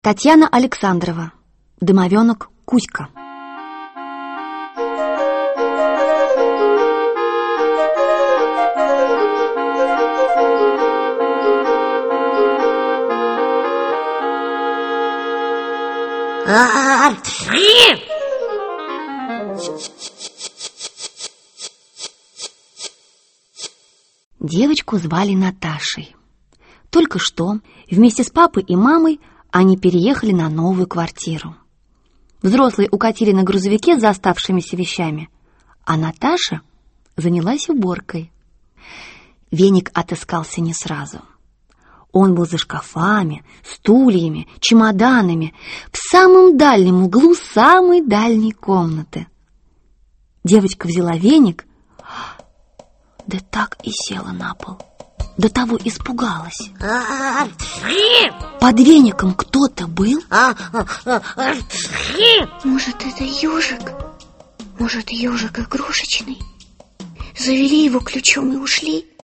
Аудиокнига Домовенок Кузька (спектакль) | Библиотека аудиокниг